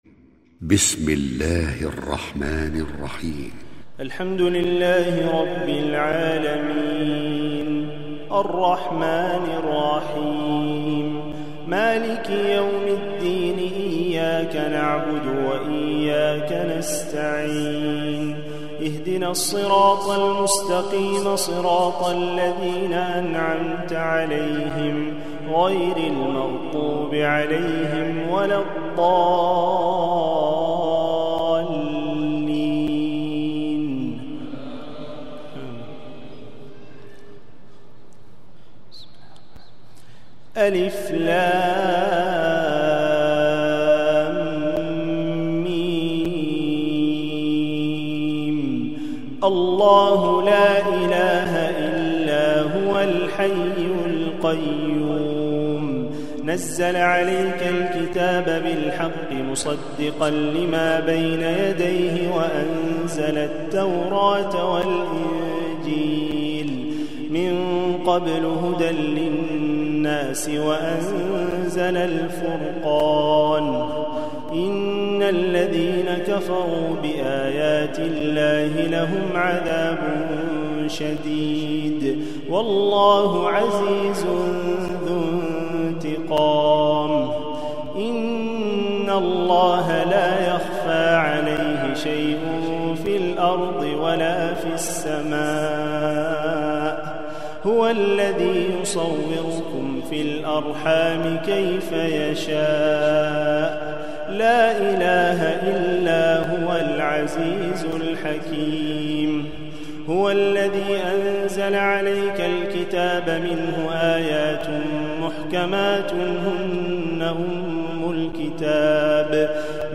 Ansarallah ØªÙ„Ø§ÙˆØ§Øª Ù…Ù† ØµÙ„Ø§Ø© Ø§Ù„ØªØ±Ø§ÙˆÙŠØ 1432Ù‡Ù€